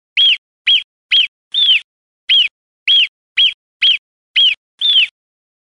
Да, звук сурка это не всегда свист и пищание, а иногда и знакомый нам богатырский храп.
Писк сурка:
pisk-surka.mp3